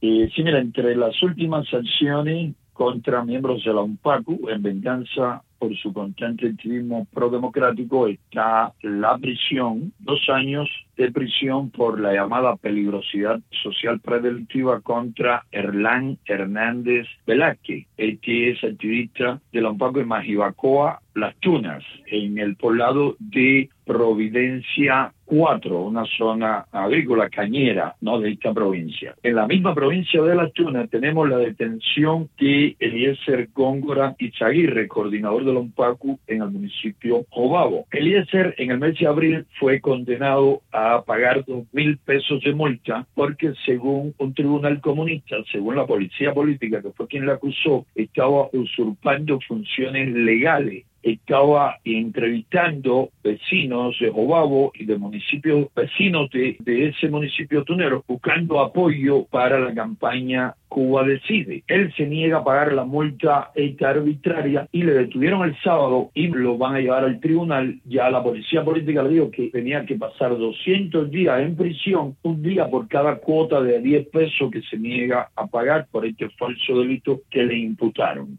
Ferrer habló con Radio Martí sobre los casos de dos activistas de UNPACU, víctimas de violencia en la prisión de mujeres de la provincia Las Tunas.